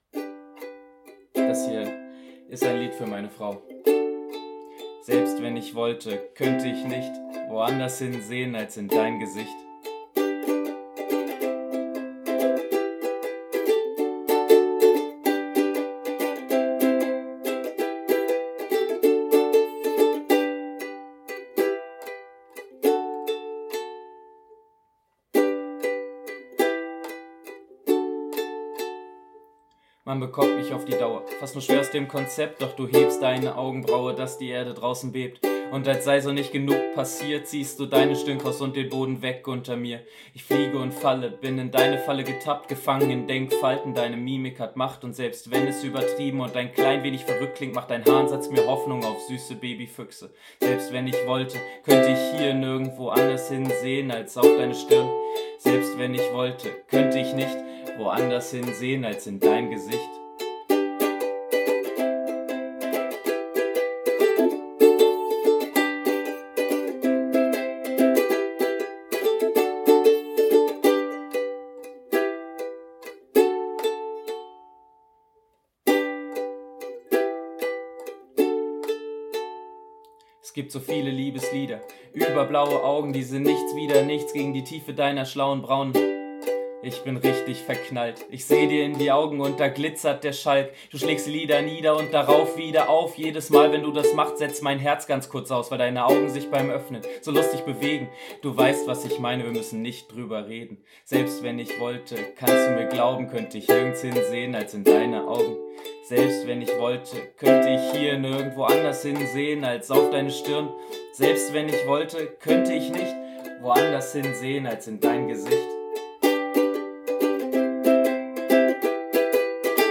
mit einer Mischung aus Gesang und Rap
Dabei begleitet er sich meist selbst mit der Ukulele, manchmal trommelt spontan ein Freund, geplant sind Akkordeonbegleitung und elektronische Beats.